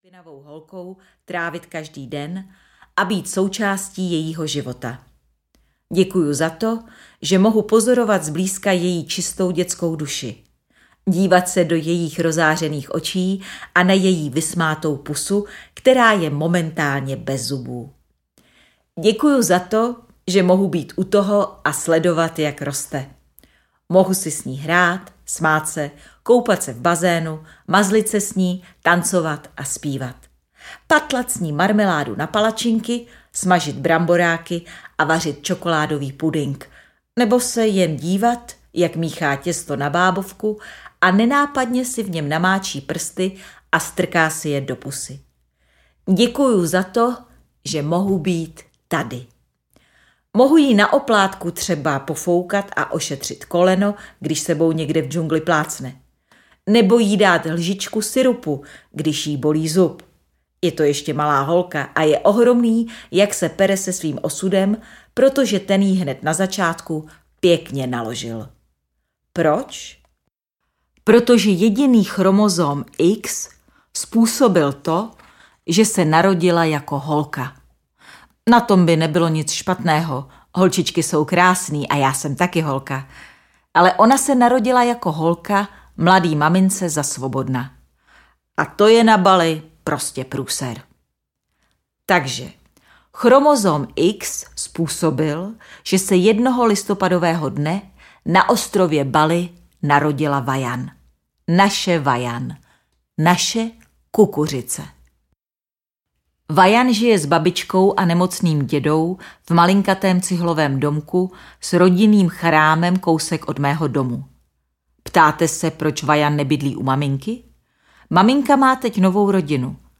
Ukázka z knihy
pribehy-ze-zeme-kde-gekoni-davaji-dobrou-noc-audiokniha